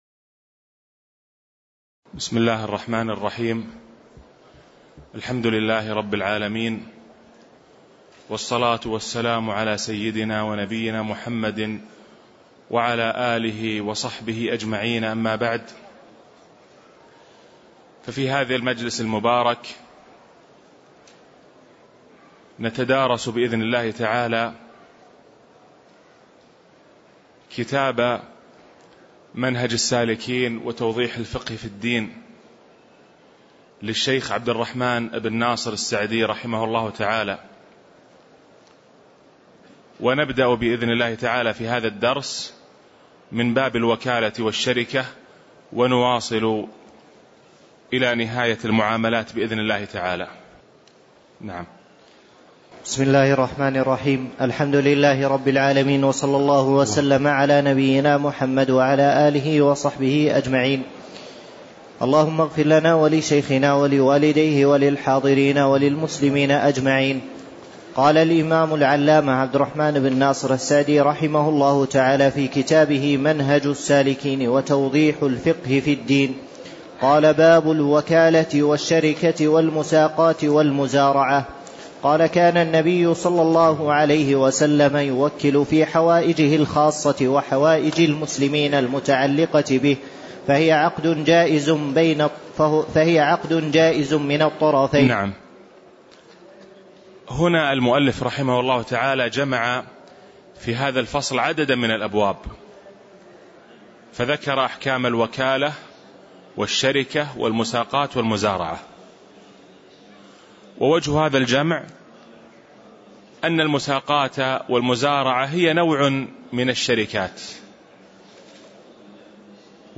تاريخ النشر ١٥ شوال ١٤٣٧ هـ المكان: المسجد النبوي الشيخ